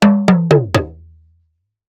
African Talking Drum FIll 2 Percussion Loop (87BPM).wav